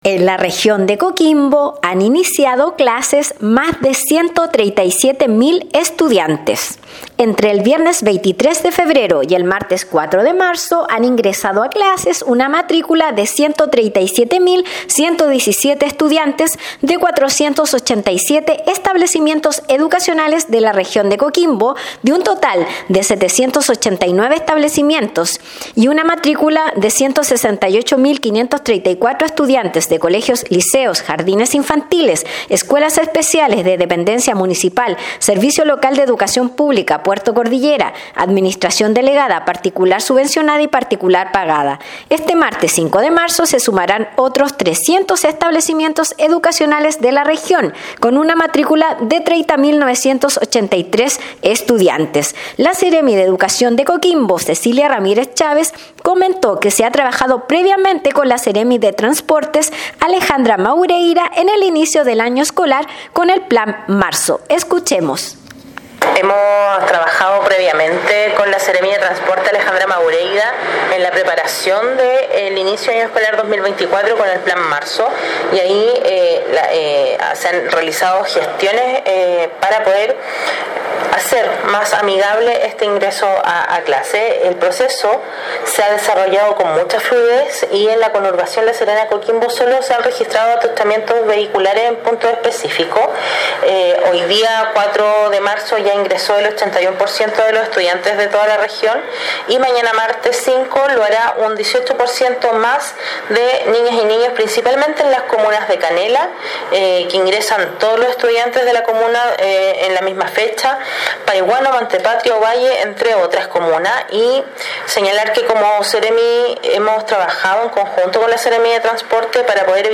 Despacho-Radial-En-La-Region-de-Coquimbo-han-iniciado-clases-mas-de-137-mil-estudiantes_.mp3